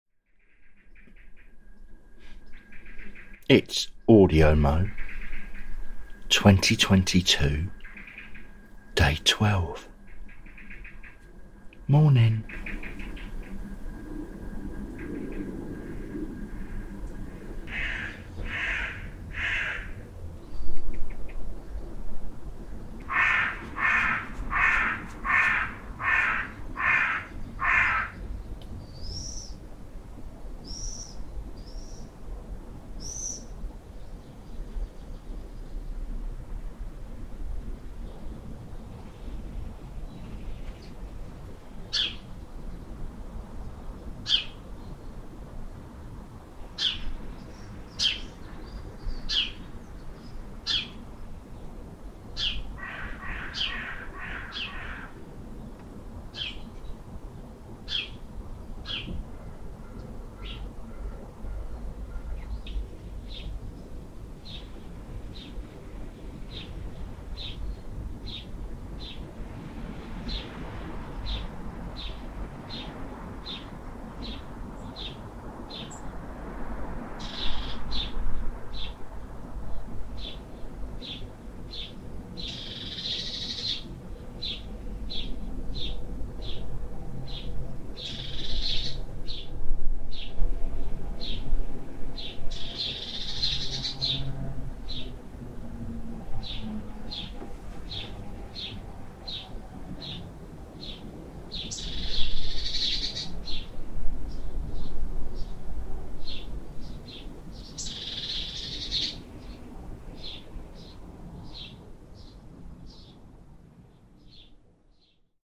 AudioMo 2022 Day 12 Good Morning from my garden
Just a couple of minutes of my local birdies waking up.